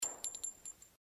bulletshells02.mp3